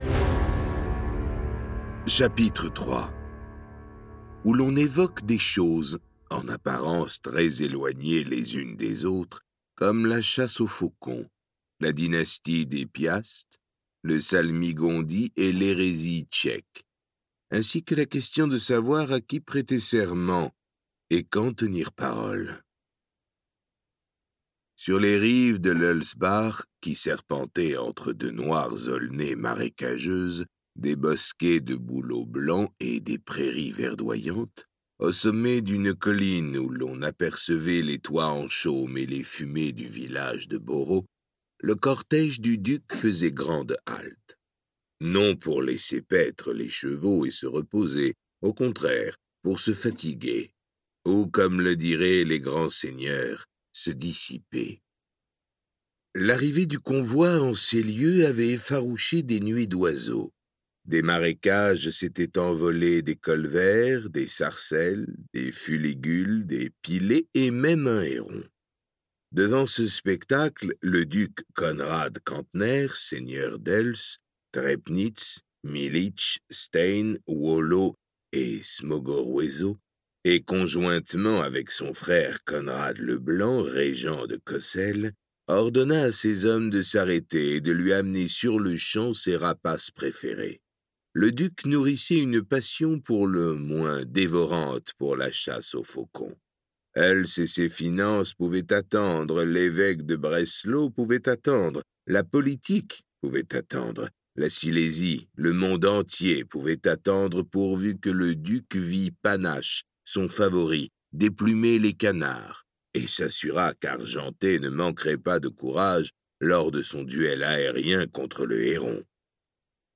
Une immersion dans une période passionnante de notre histoire, au fabuleux souffle épique !Ce livre audio est interprété par une voix humaine, dans le respect des engagements d'Hardigan.